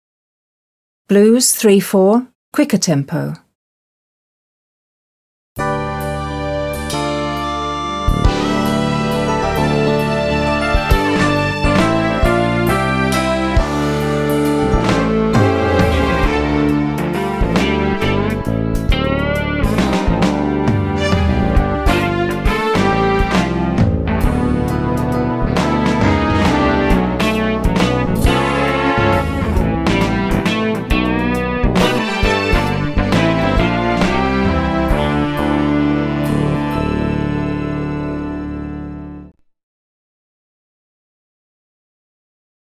Blues 34 - quicker
Rehearsal & Practice Music